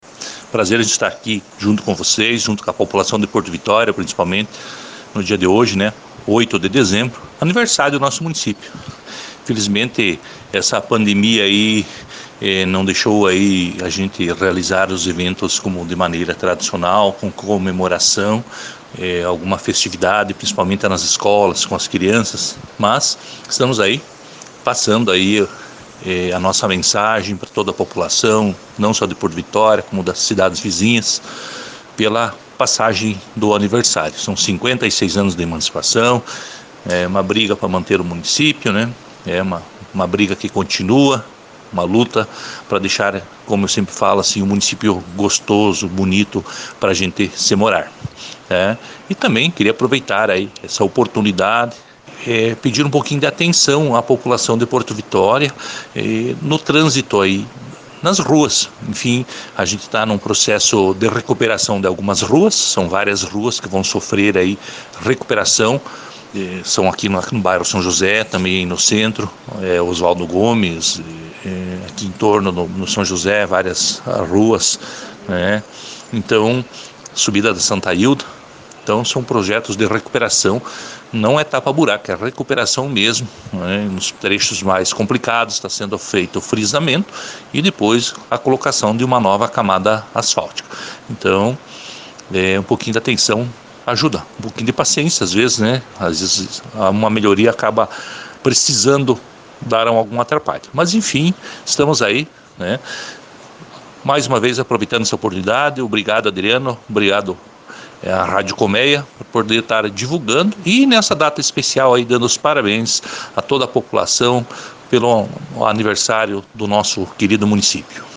Em entrevista à Rádio Colmeia nesta terça-feira, o atual prefeito da cidade, Kurt Nielsen, parabenizou o lugar pela passagem de seu aniversário.